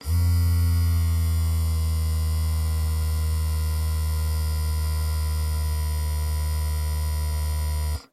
Звуки бипера и его вибрация